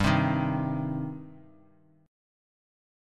Gsus4#5 chord